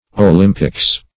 Olympic games \O*lym"pic games\, or Olympics \O*lym"pics\